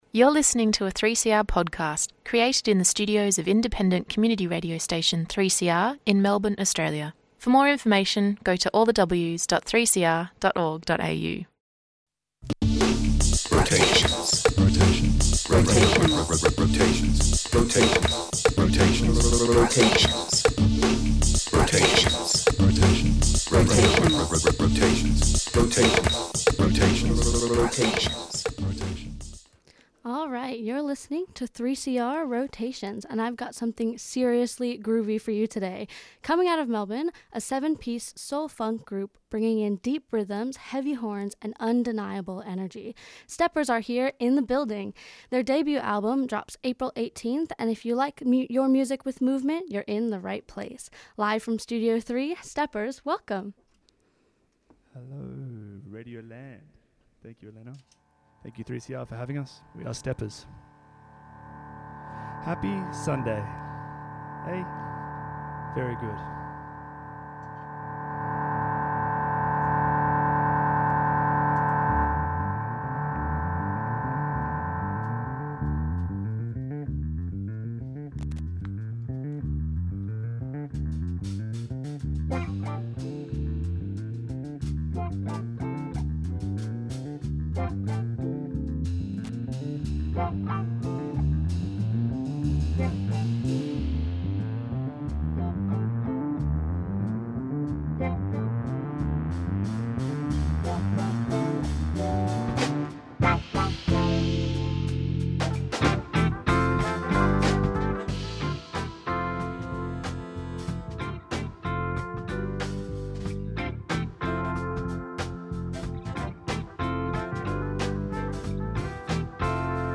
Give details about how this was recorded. A collection of longer live sets recorded by local bands and released throughout the year, plus a few requests.